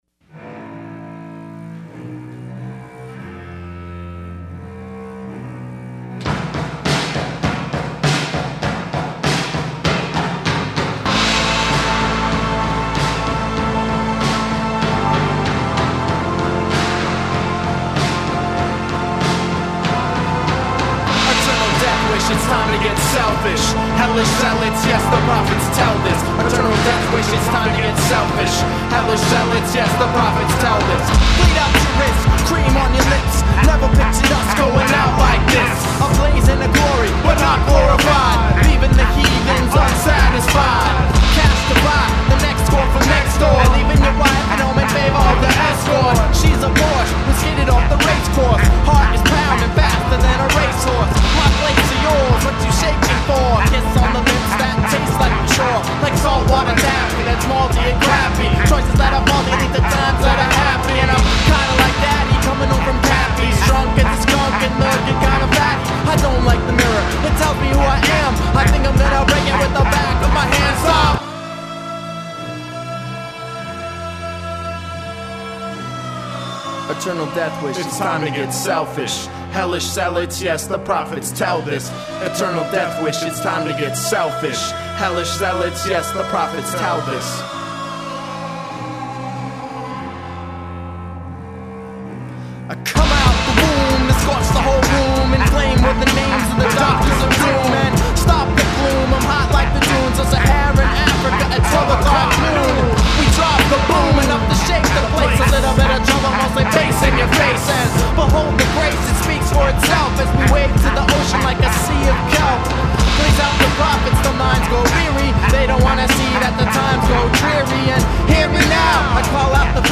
No doubt is this the funnest smash-danciest hip-hop around.